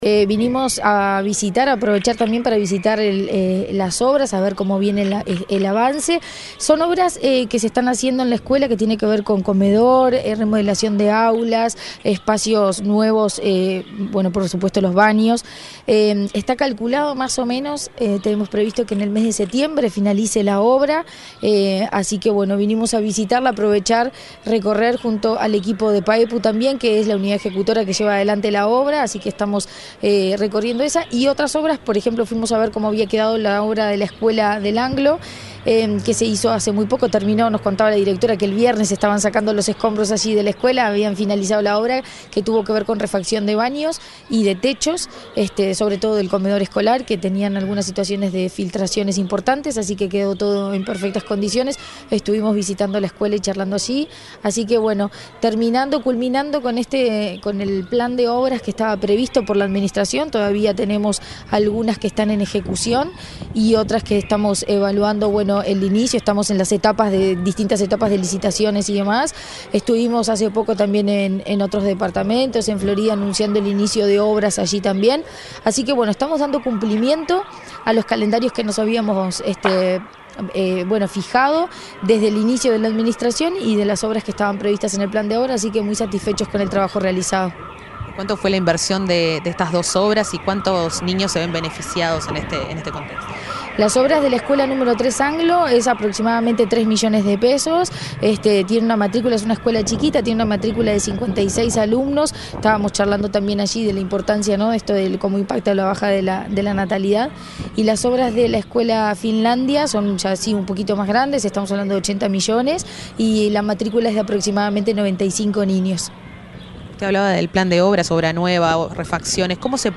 Entrevista a la presidenta de la ANEP, Virginia Cáceres
Entrevista a la presidenta de la ANEP, Virginia Cáceres 09/07/2024 Compartir Facebook X Copiar enlace WhatsApp LinkedIn La presidenta de la Administración Nacional de Educación Pública (ANEP), Virginia Cáceres, dialogó con Comunicación Presidencial, el pasado lunes 8, durante una recorrida por las obras que realizan en dos escuelas de Fray Bentos, departamento de Río Negro.